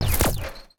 UIClick_Menu Double Hit Rumble Tail 02.wav